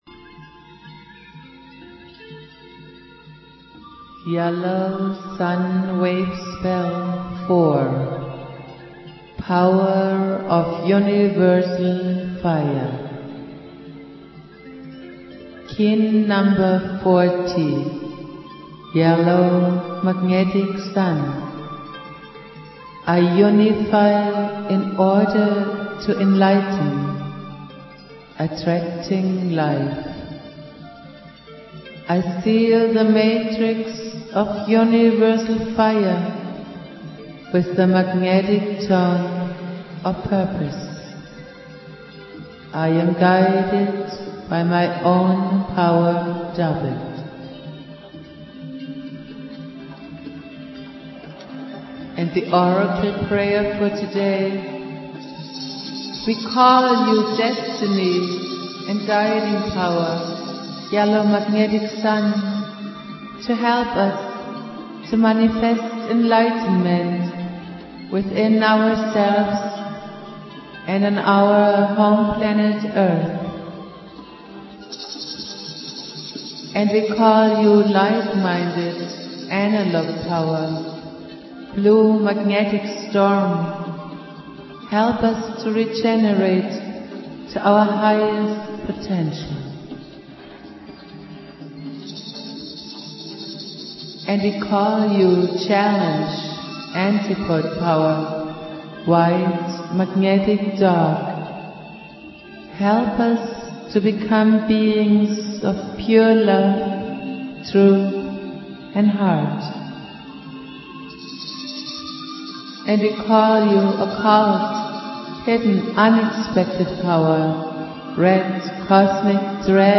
Prayer
produced at High Flowing Recording Studio.
Jose's spirit and teachings go on Jose Argüelles playing flute.